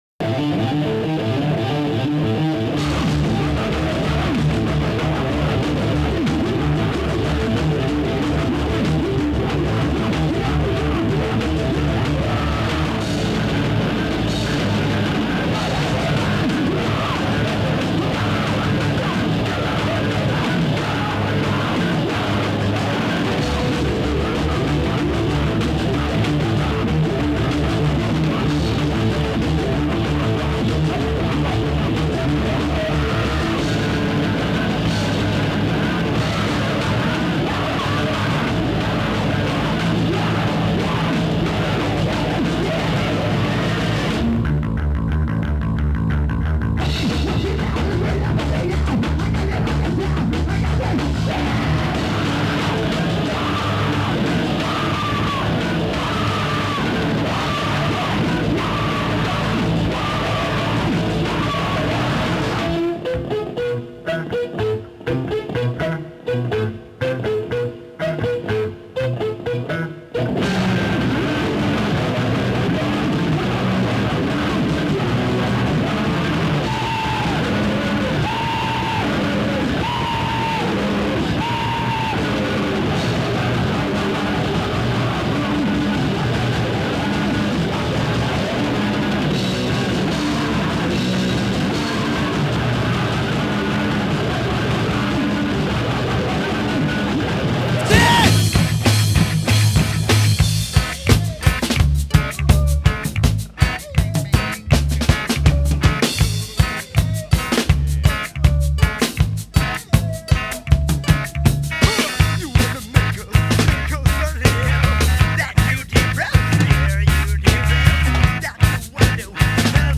bass
guitar & screams
drums
throat